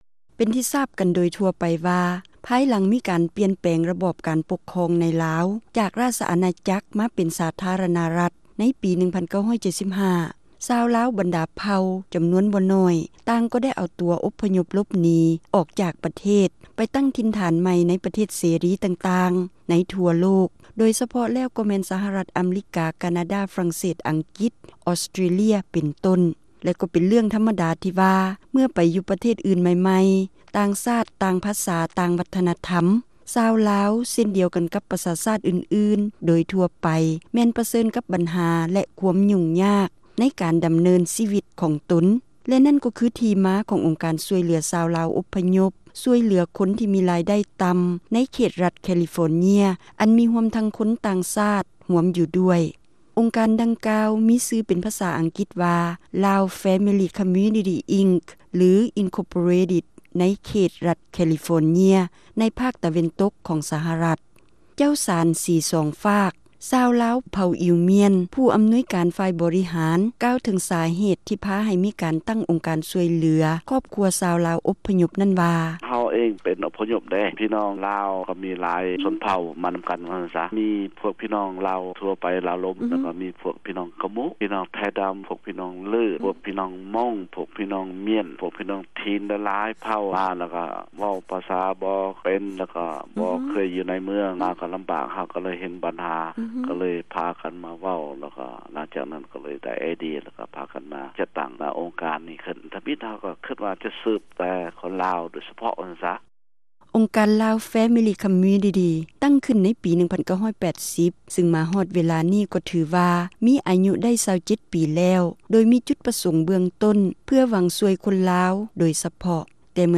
ຣາຍການໜໍລຳ ປະຈຳສັປະດາ ວັນທີ 29 ເດືອນ ມິນາ ປີ 2007